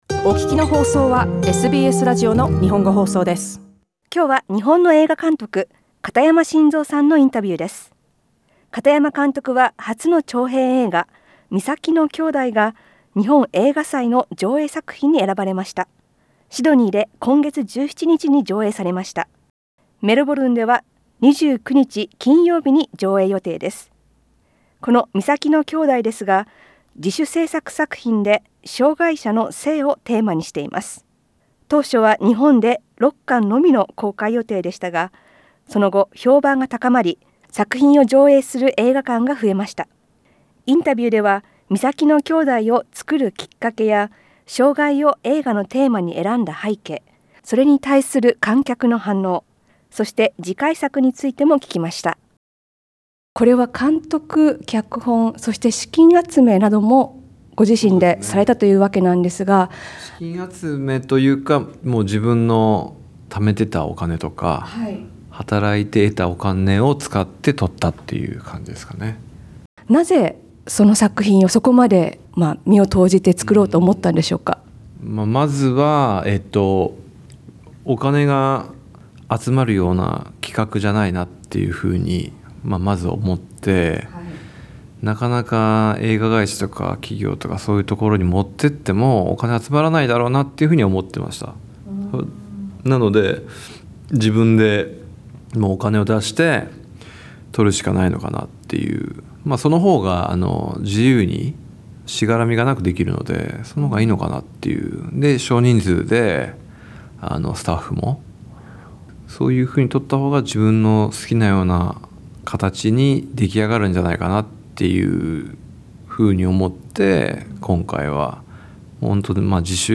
SBSの日本語放送